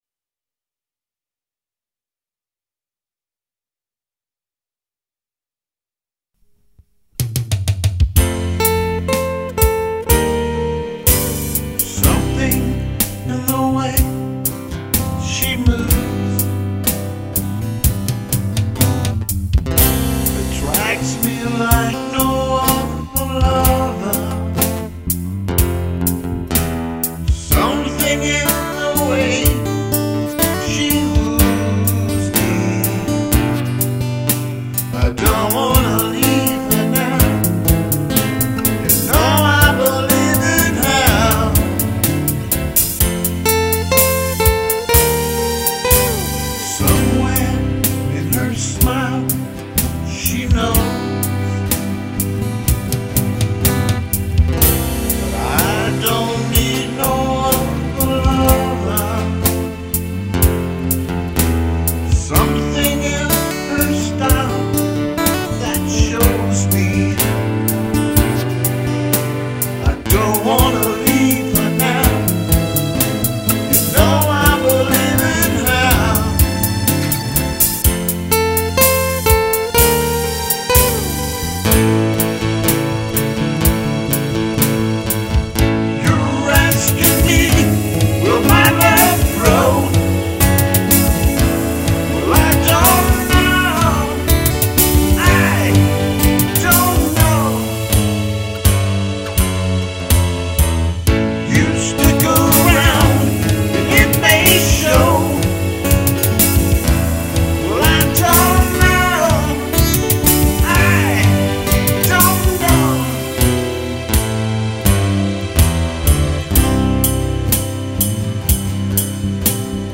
AT THE STATE FAIR OF TEXAS 2010